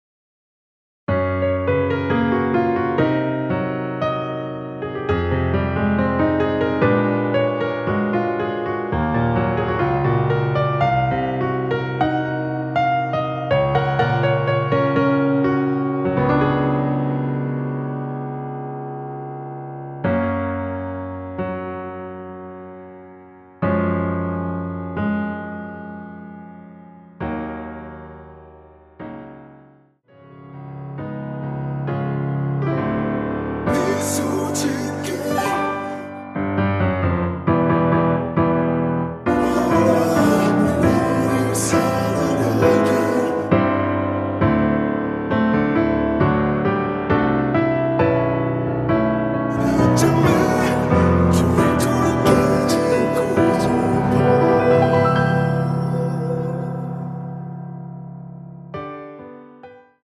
원키에서(-2)내린 코러스 포함된 MR입니다.(미리듣기 참조)
F#
앞부분30초, 뒷부분30초씩 편집해서 올려 드리고 있습니다.